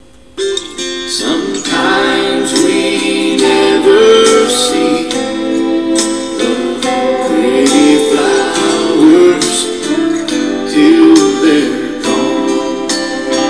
is a singer/songwriter and plays piano.
He also plays bass guitar for the group.
sings lead and harmony vocals